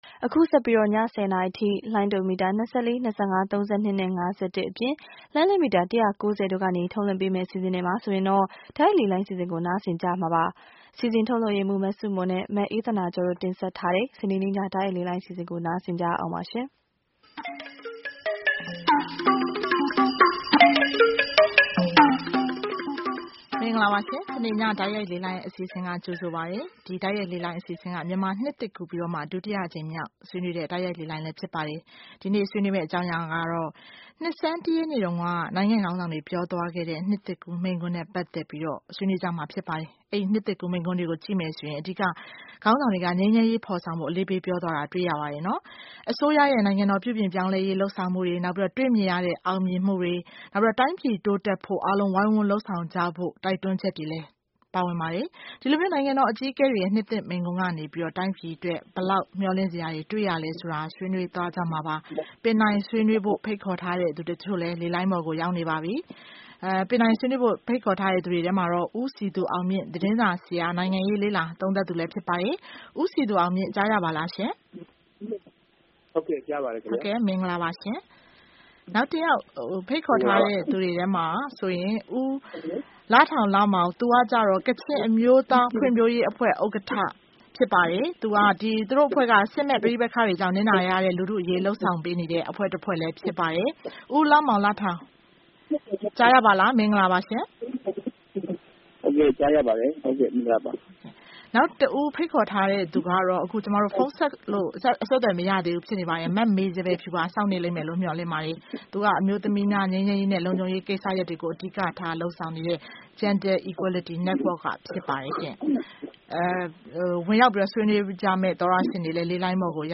နိုင်ငံတော်အကြီးအကဲများရဲ့ နှစ်သစ်မိန့်ခွန်း (တိုက်ရိုက်လေလှိုင်း)